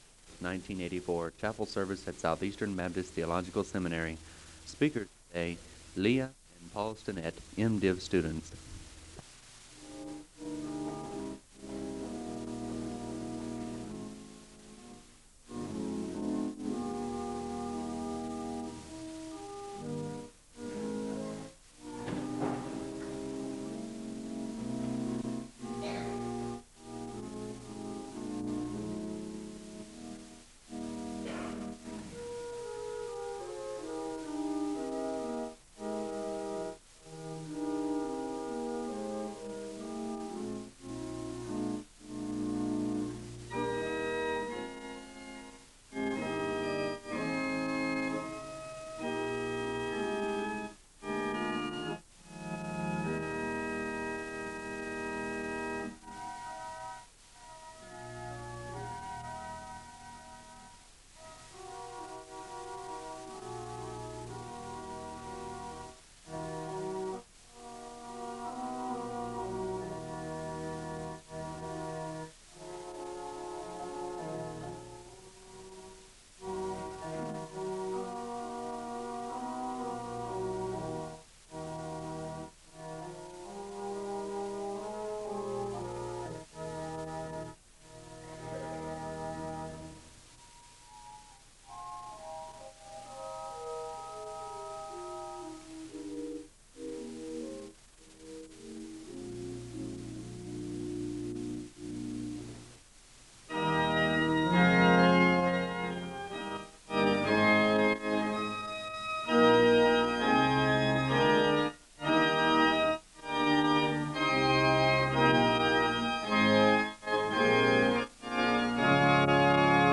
The service begins with the song “Jesus Paid It All” being played on the organ (00:00-02:07). The speaker delivers the Scripture reading from Psalm 95, and a word of prayer is given (02:08-03:43). A song of worship is performed (03:44-07:23).